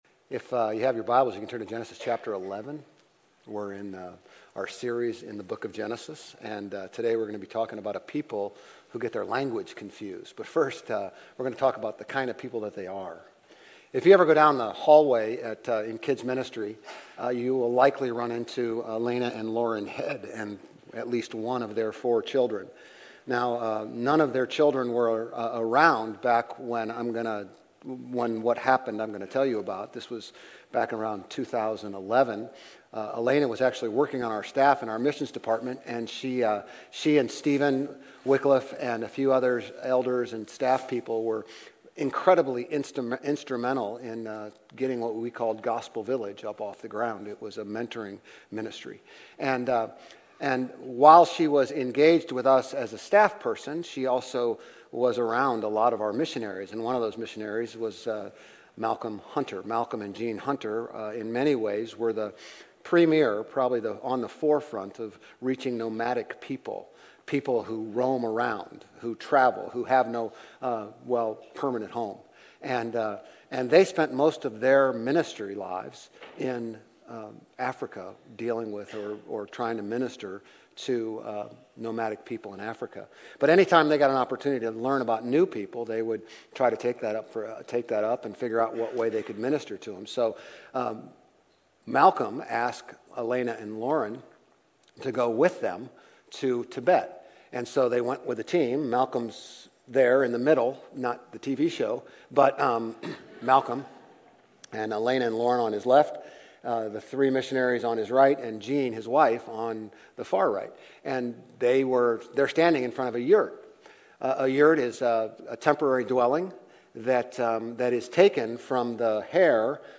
Grace Community Church Old Jacksonville Campus Sermons Genesis 11 - Tower of Babel Oct 07 2024 | 00:34:55 1x 00:00 / 00:34:55 Subscribe Share RSS Feed Share Link Embed